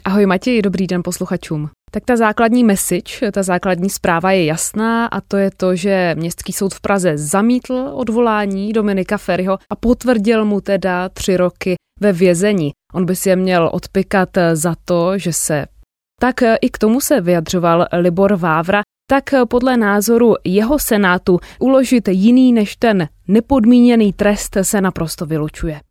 Czech_Female_Sourse.wav